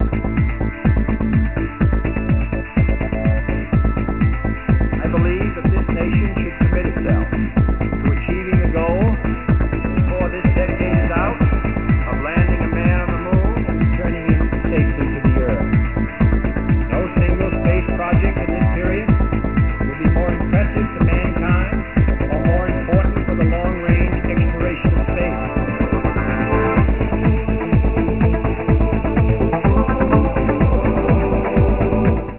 This sound bite includes the speech by JFK.